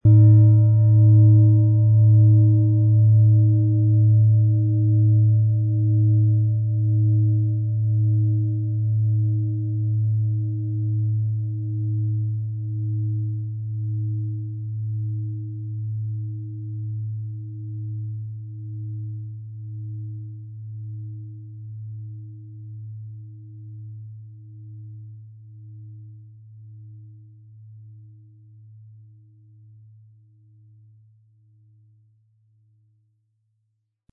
Tibetische Herz-Bauch-Gelenk- und Fuss-Klangschale
HerstellungIn Handarbeit getrieben
MaterialBronze